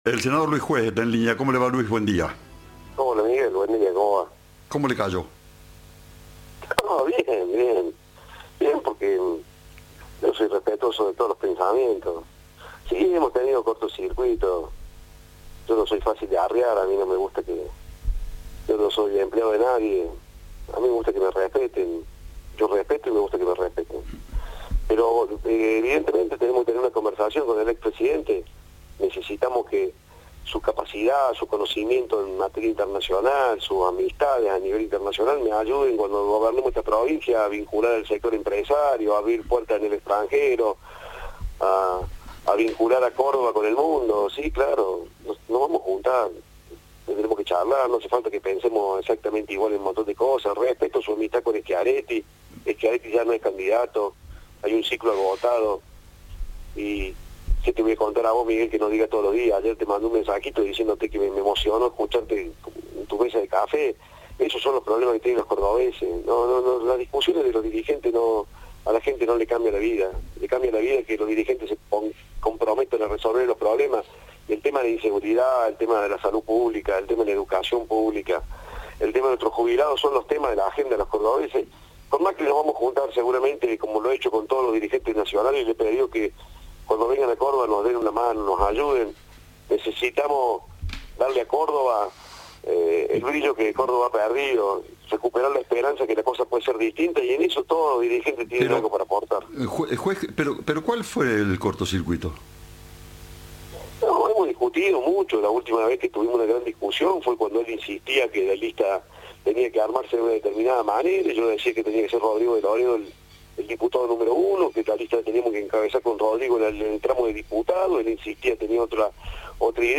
En diálogo con Cadena 3, el candidato a gobernador de JxC en Córdoba admitió diferencias con el expresidente.